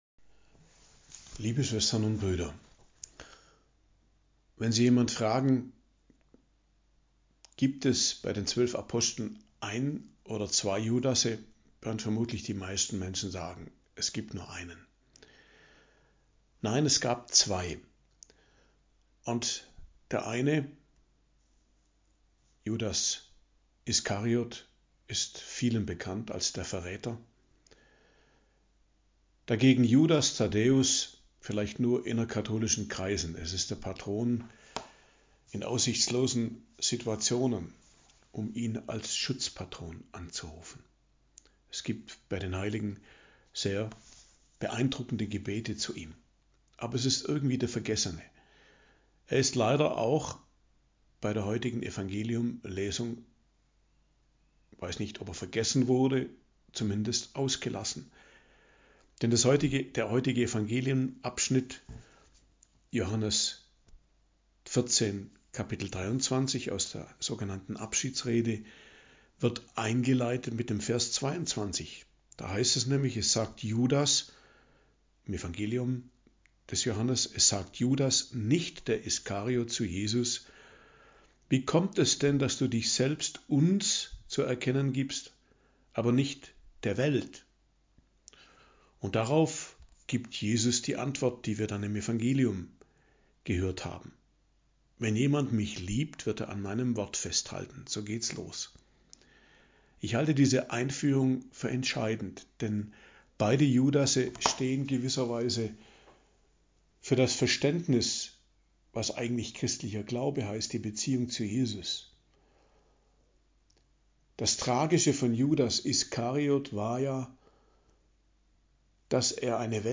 Predigt zum 6. Sonntag der Osterzeit, 25.05.2025 ~ Geistliches Zentrum Kloster Heiligkreuztal Podcast